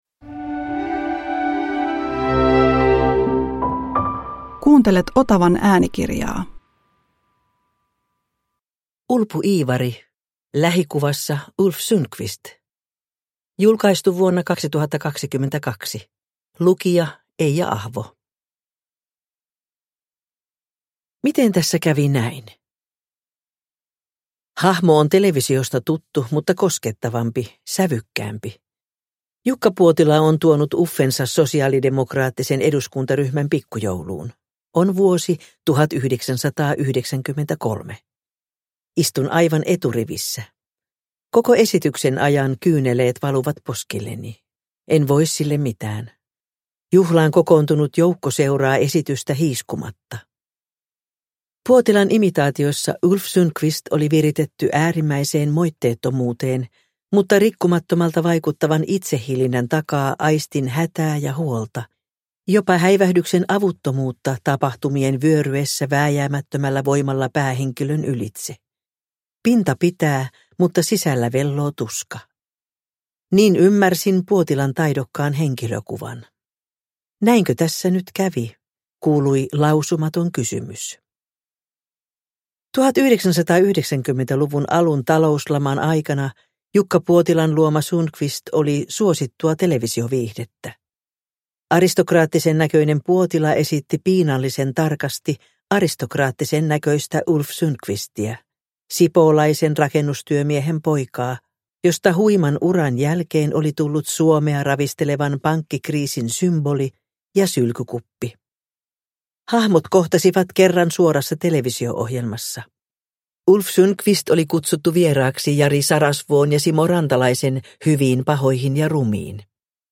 Lähikuvassa Ulf Sundqvist – Ljudbok – Laddas ner